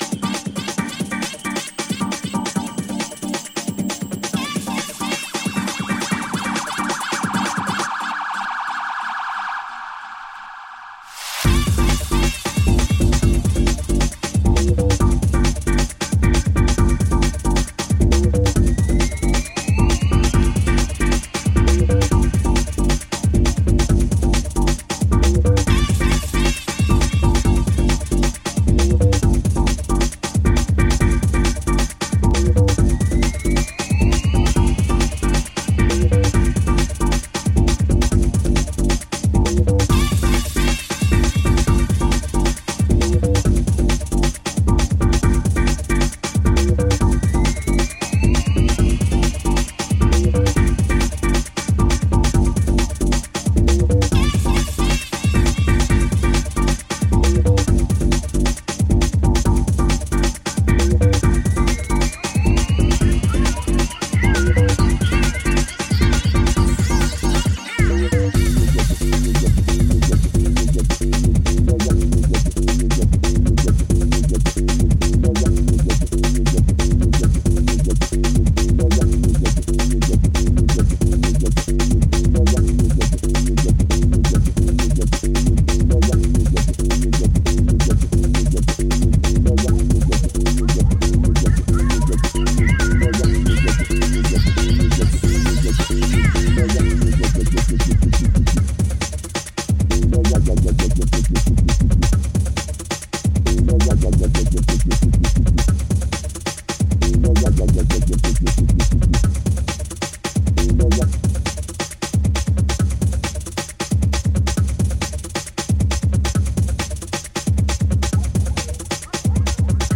ディープでファットな低音が気持ち良い、バッチリ世界水準のフロアチューンが揃う傑作。